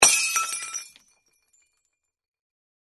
Звуки разбитого стекла
Звук разбивающейся стеклянной бутылки